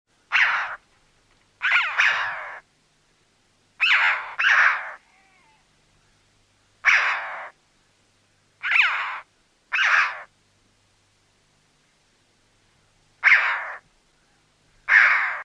Birdsong 1